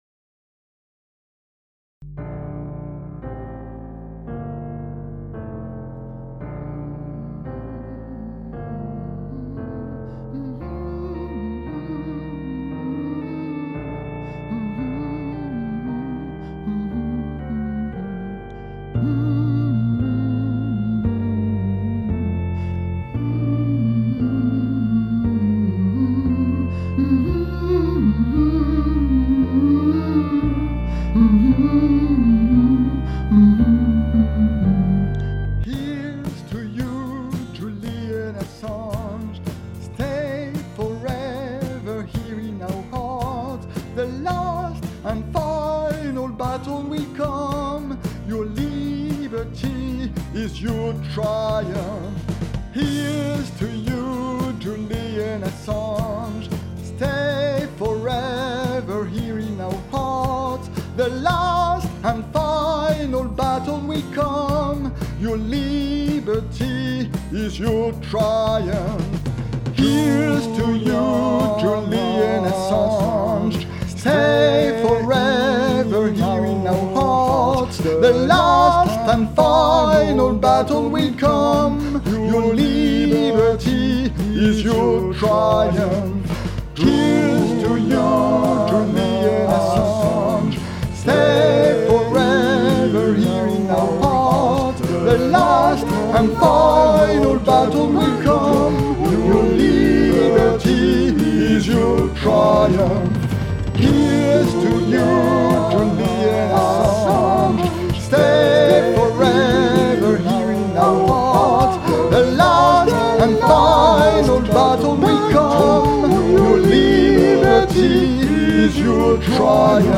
4 voix .